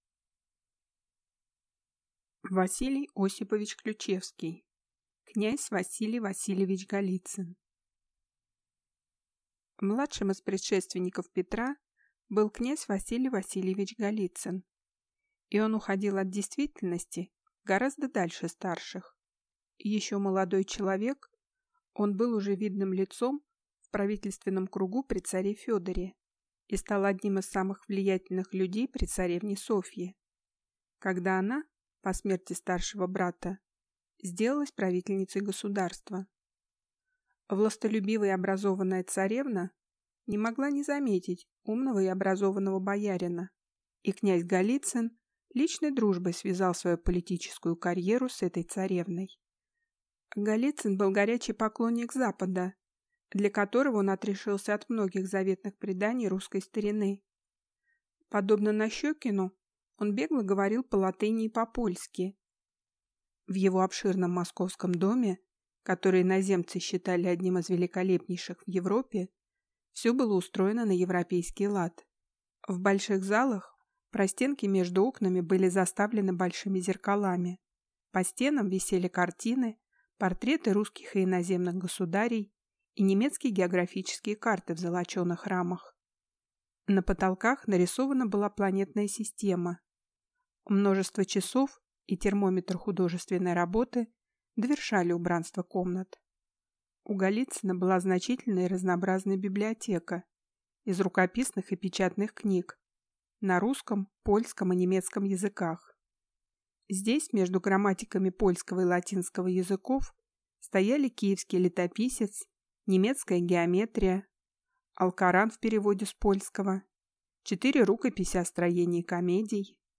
Аудиокнига Князь Василий Васильевич Голицын | Библиотека аудиокниг